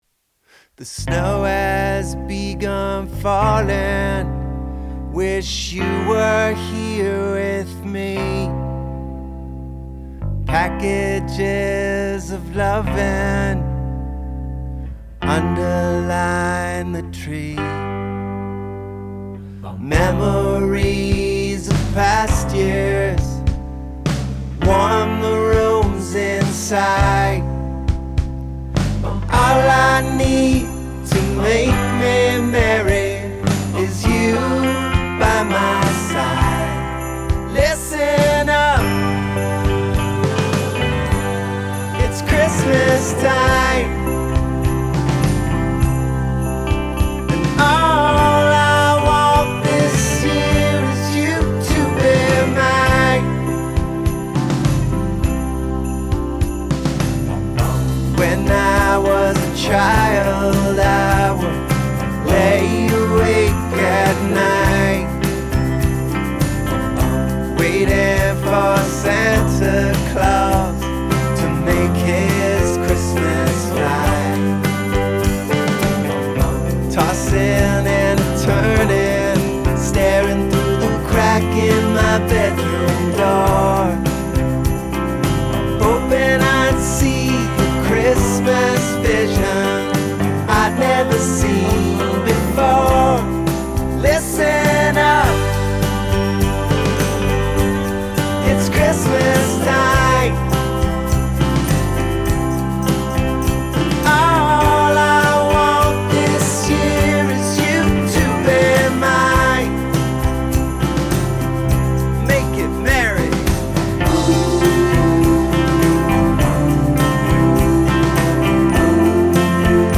oh so subtle ear wormy chorus